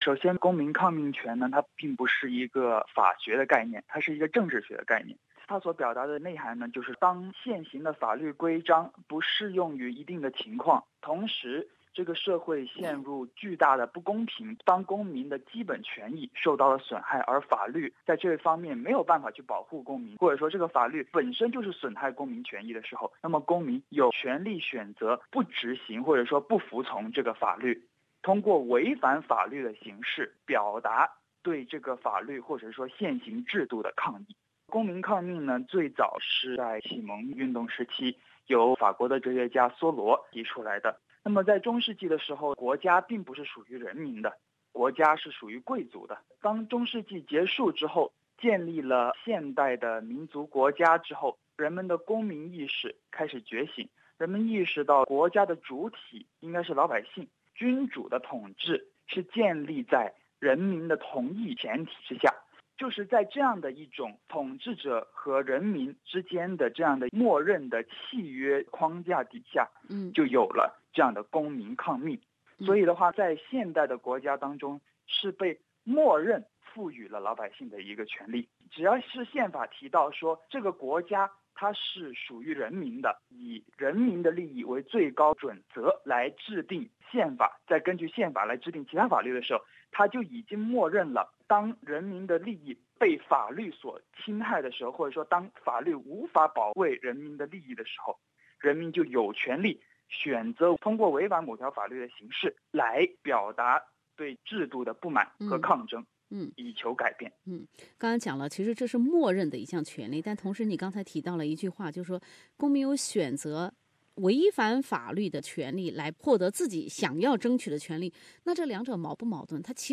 采访为嘉宾观点，不代表本台立场 READ MORE “香港不是中国”, 将中国读作支那，香港议员利用宣誓作抗议 陈方安生与李柱铭呼吁中国坚守“一国两制” 陈方安生李柱铭：请澳洲为香港自由发声 香港铜锣湾书店人员失踪之谜——点击观看 分享